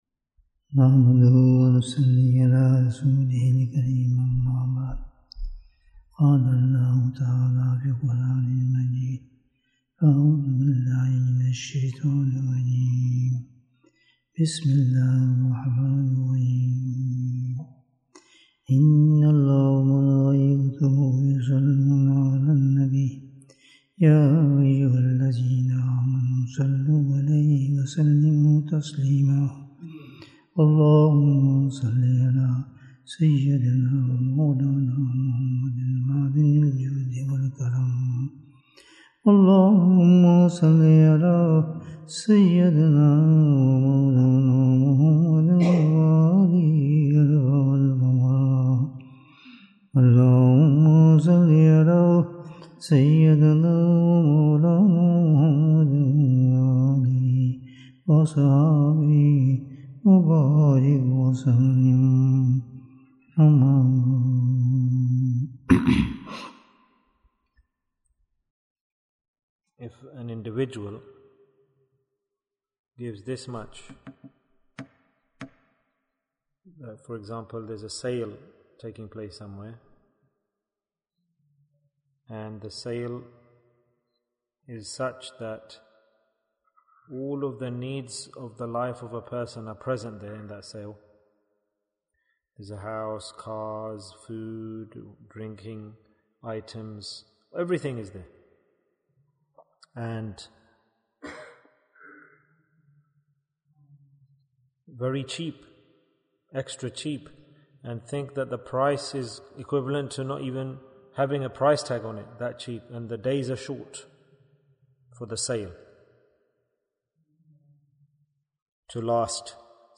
Which is the Straight Path to Jannah? Bayan, 72 minutes10th November, 2022